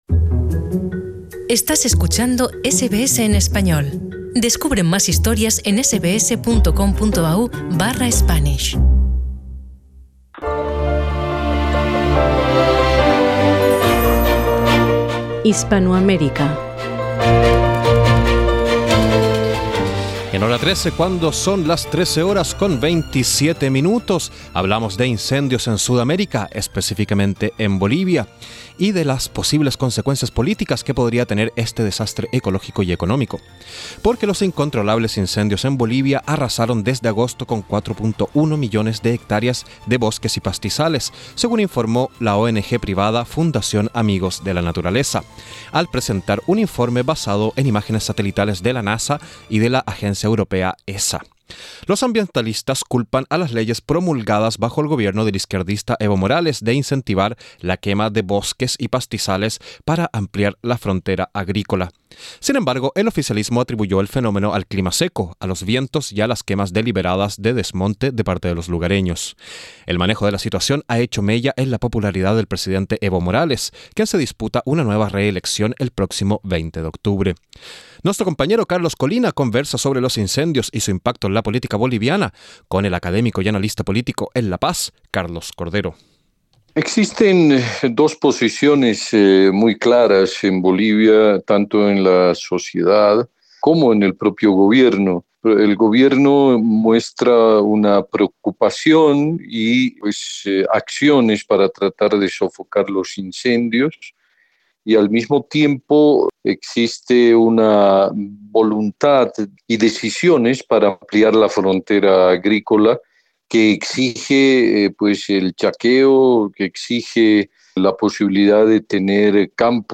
SKIP ADVERTISEMENT Entrevista con el académico y analista político en La Paz